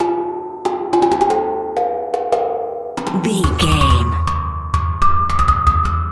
Short musical SFX for videos and games.,
Sound Effects
Ionian/Major
aggressive
bright
epic
intense
driving
bouncy
energetic
funky
heavy